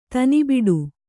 ♪ tani biḍu